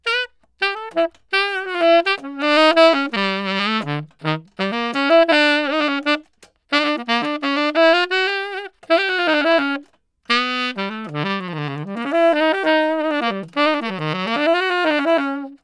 Famille : vent/bois
Descriptif : c’est un instrument qui est semblable à la clarinette, mais il est en métal et son tube est conique, ce qui lui donne sa sonorité différente.
Saxophone